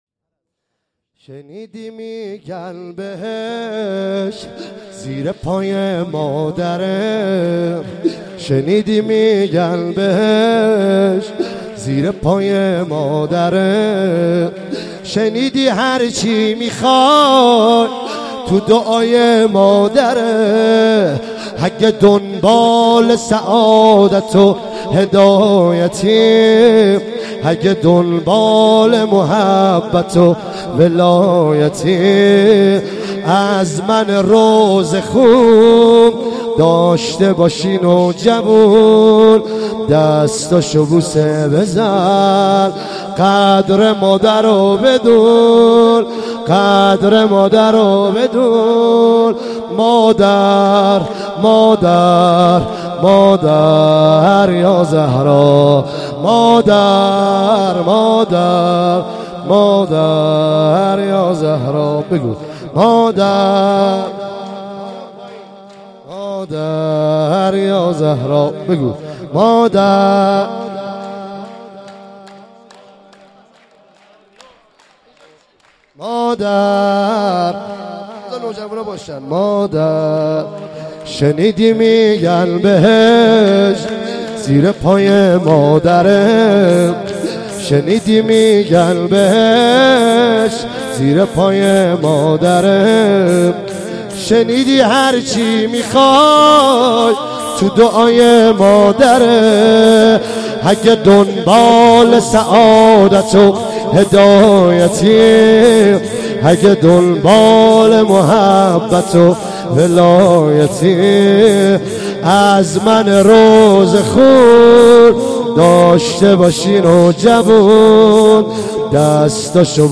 عنوان ولادت حضرت زهرا ۱۳۹۹ – شاندیز مشهد
سرود